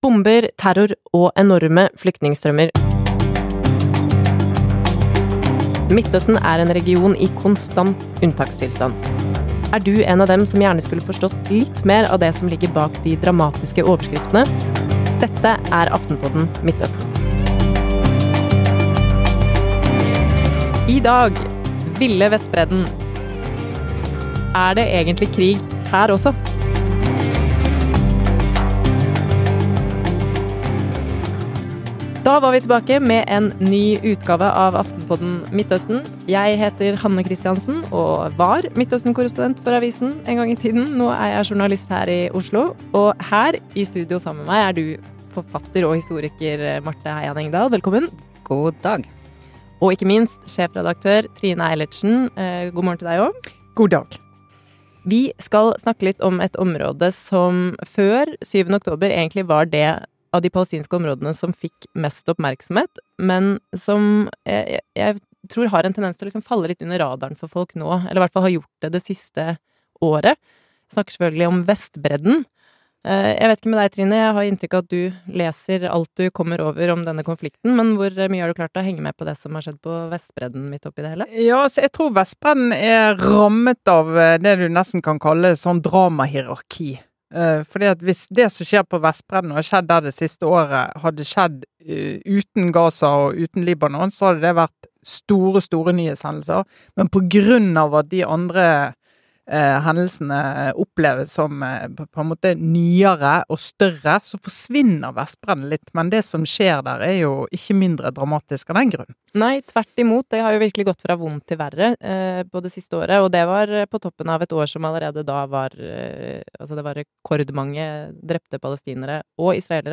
I denne ukens episode snakker panelet om den historiske utviklingen som har skapt en voldsspiral på Vestbredden, og om ABC-puslespillet som gjør dette området så komplisert.